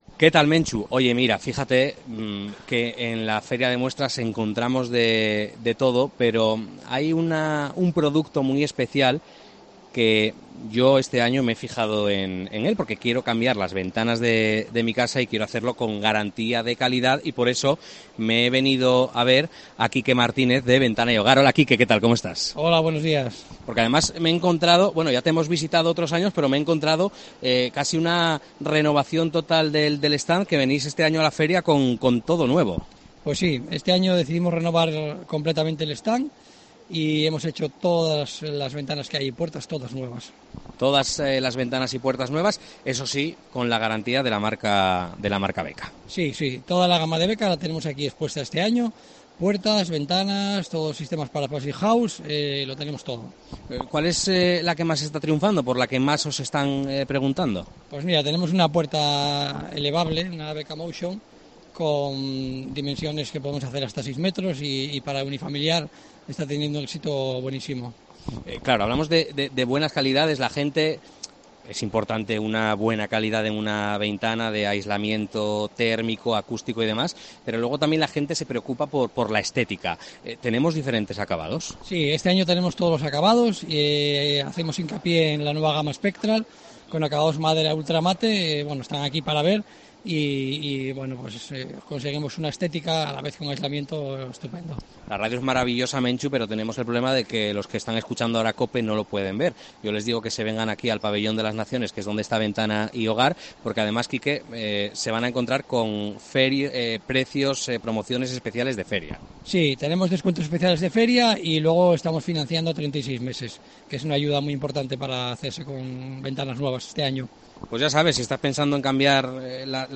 En el especial de COPE desde el Recinto Ferial Luis Adaro
FIDMA 2023: entrevista